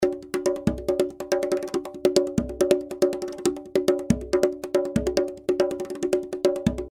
140 BPM conga loops (18 variations)
Conga loops in salsa style at 140 bpm.
Real conga loops played by professional percussion player at 140 BPM.
(AKG C-12 VR , 2 x AKG 451B for room and stereo).
*- room reverb was added to the conga loops in the preview.